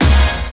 Stab 7k
Stab.wav